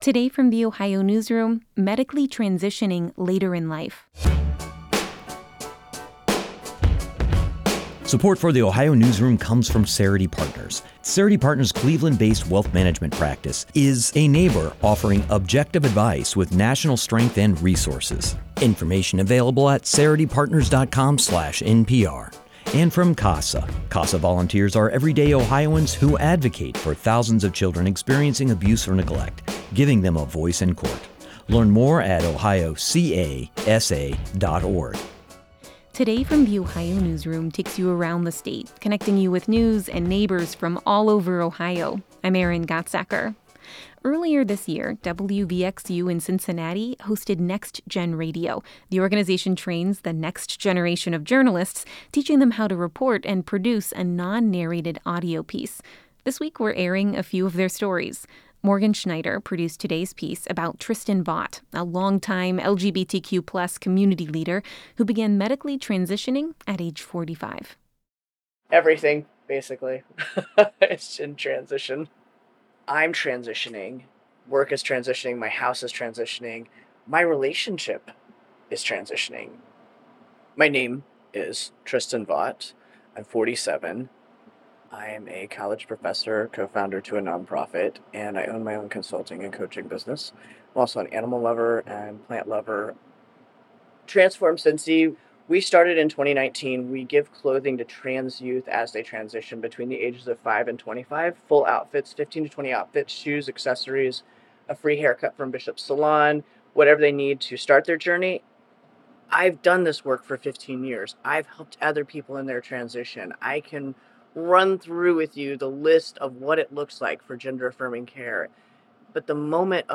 The organization trains the next generation of journalists, teaching them how to report and produce a non-narrated audio piece. This week, we're sharing a few of their stories.